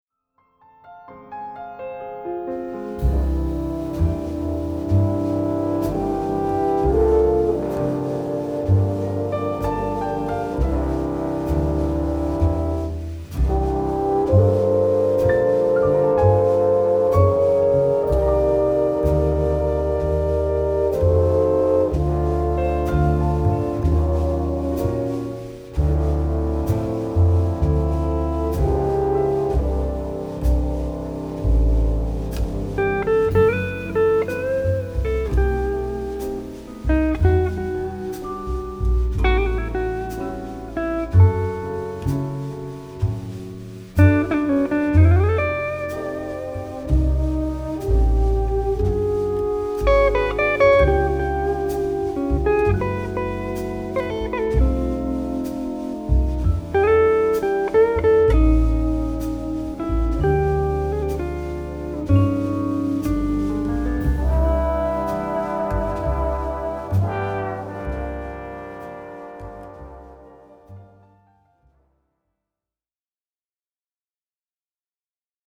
trumpets
alto sax & alto flute
baritone sax
French horns
vibes